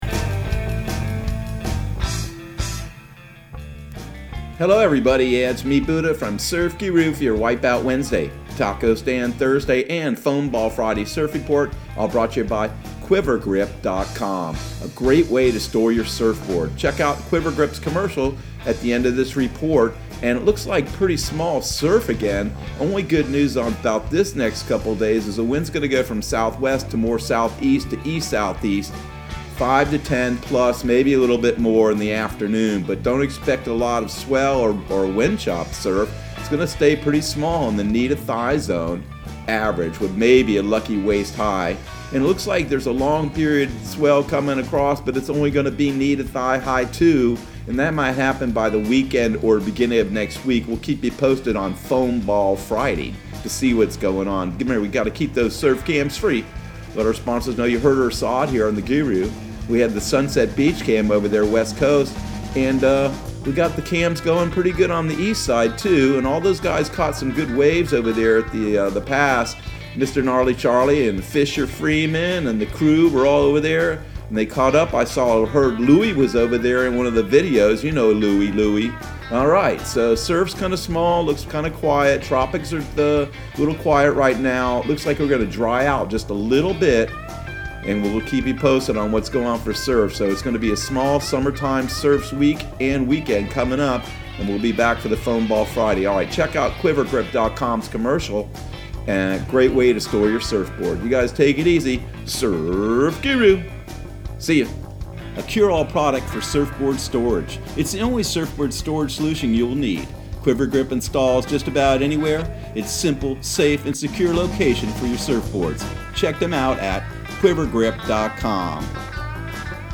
Surf Guru Surf Report and Forecast 06/10/2020 Audio surf report and surf forecast on June 10 for Central Florida and the Southeast.